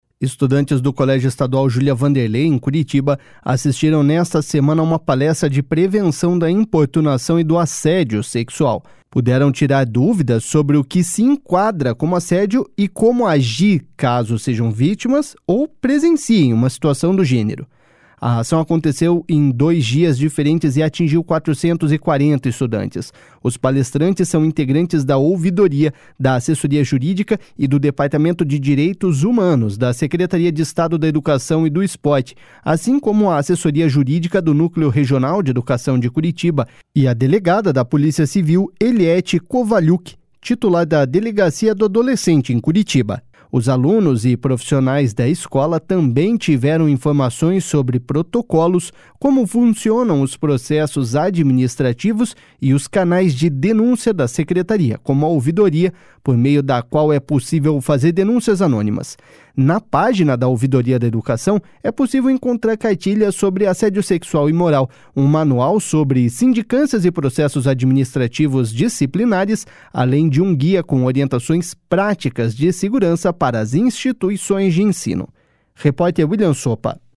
ALUNOS DA REDE ESTADUAL ASSISTEM PALESTRA.mp3